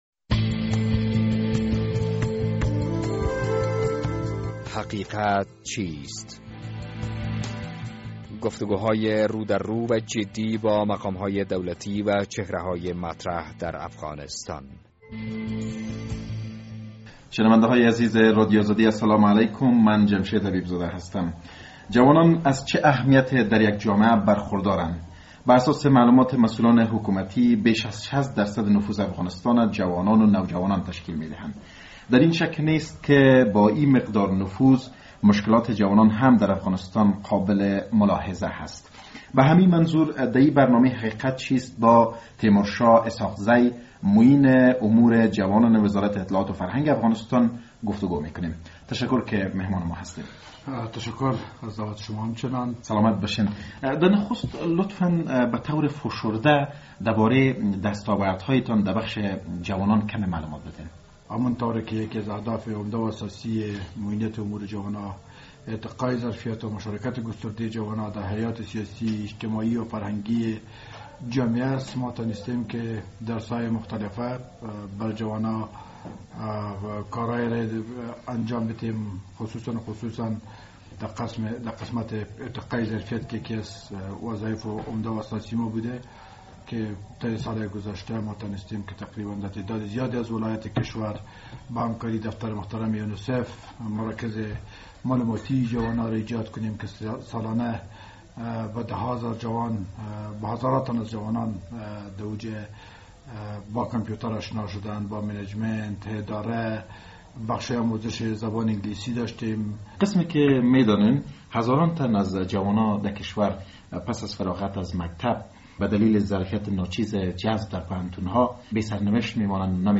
در این برنامه حقیقت چیست با تیمور شاه اسحاق زی معین امور جوانان وزارت اطلاعات و فرهنگ گفتگو کره ایم.